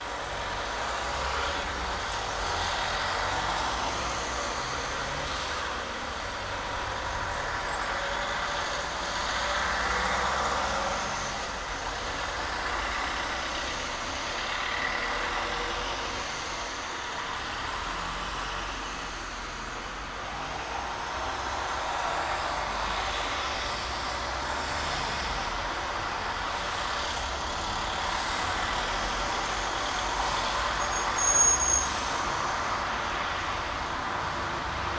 Level adjusted street noise from our testing setup with no headphones.
Level adjusted street noise from our testing rig with the Sony WH-1000XM6 ANC enabled.
street-noise-level-matched.wav